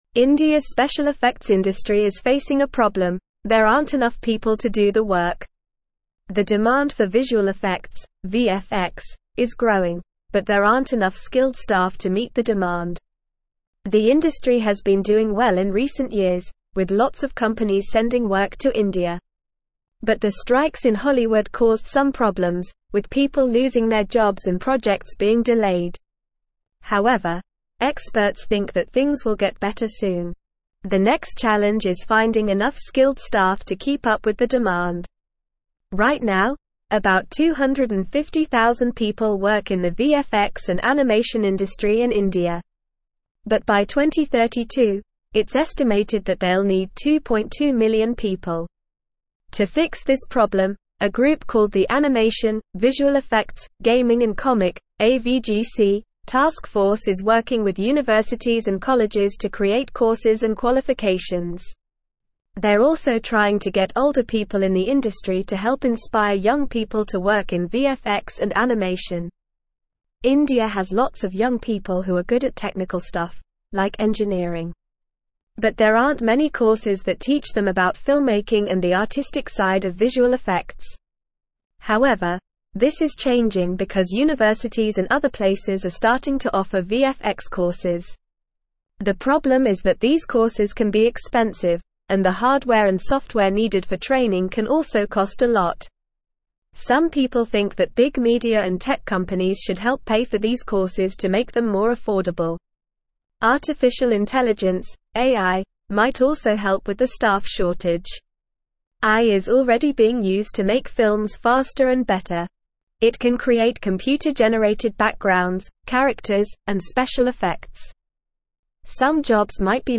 Normal
English-Newsroom-Upper-Intermediate-NORMAL-Reading-Indias-VFX-Industry-Faces-Staff-Shortage-as-Demand-Grows.mp3